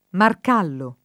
[ mark # llo ]